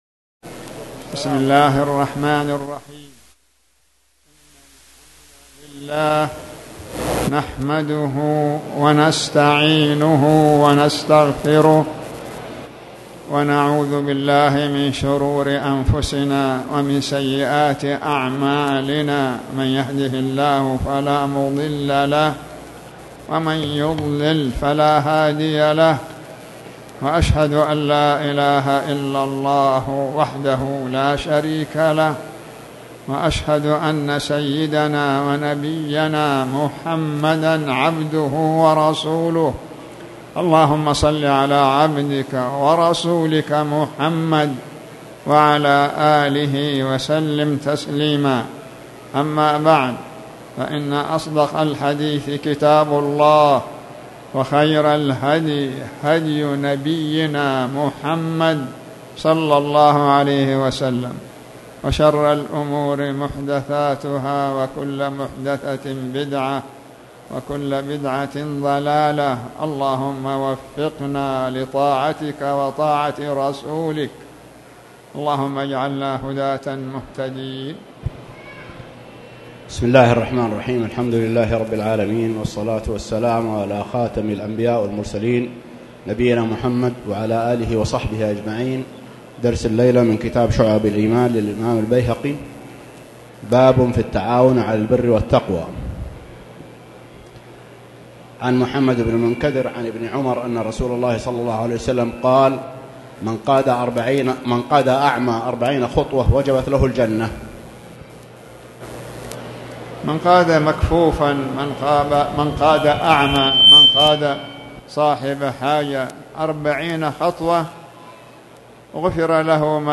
تاريخ النشر ٢٤ شوال ١٤٣٨ هـ المكان: المسجد الحرام الشيخ